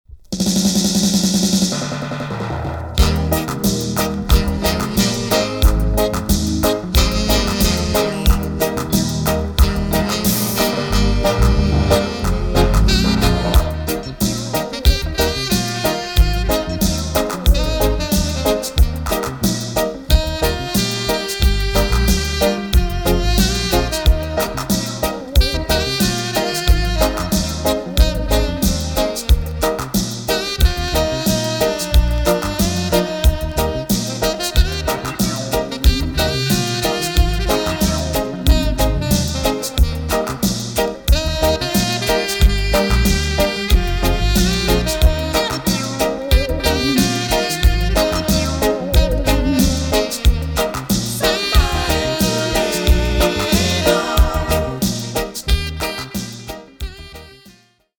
EX 音はキレイです。
SAX